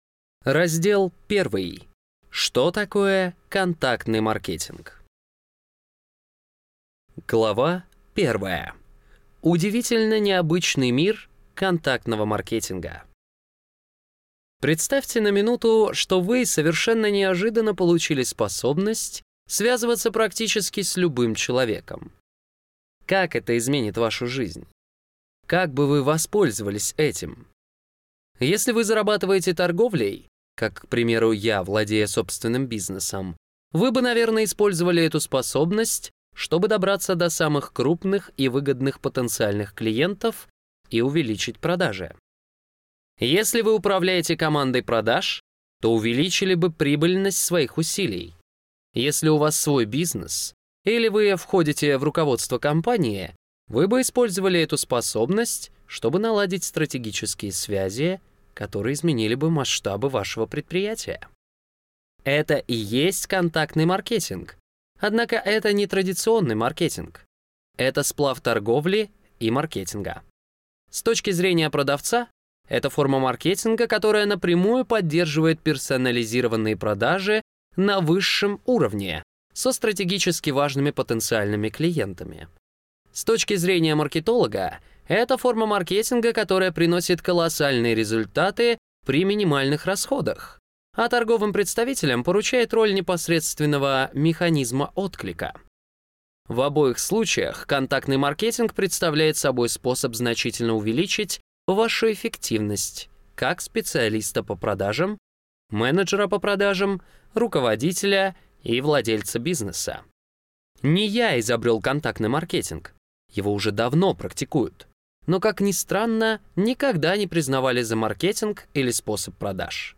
Прослушать фрагмент аудиокниги Как добиться встречи с кем угодно.